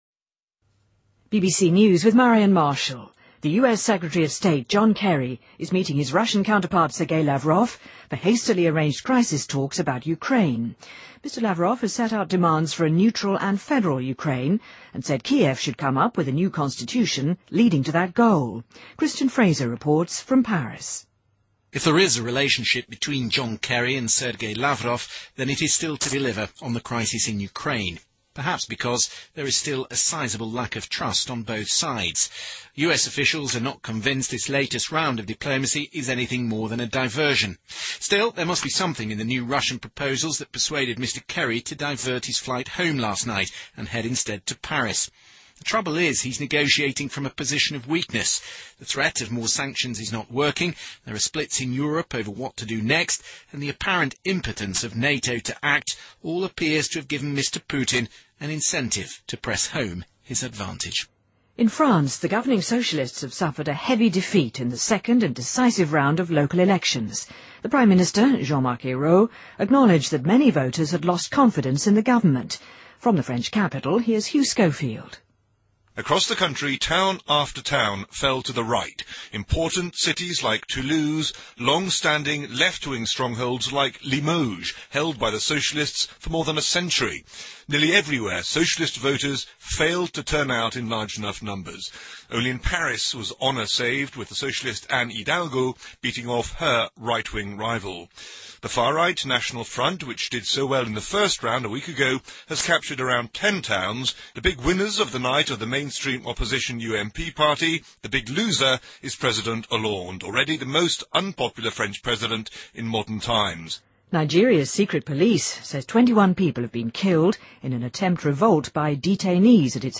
BBC news,2014-03-31